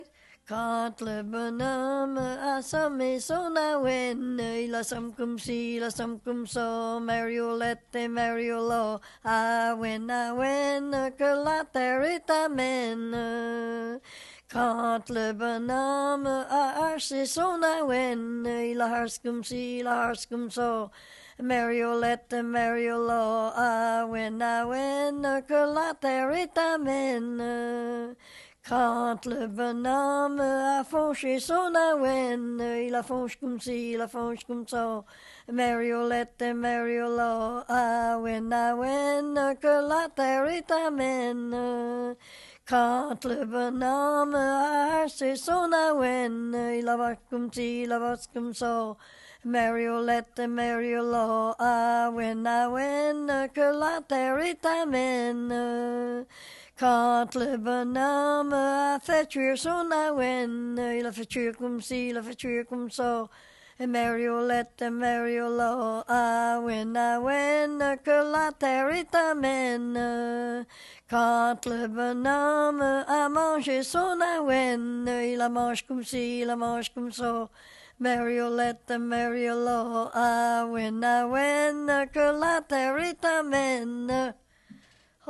Chanson Item Type Metadata
Emplacement Upper Ferry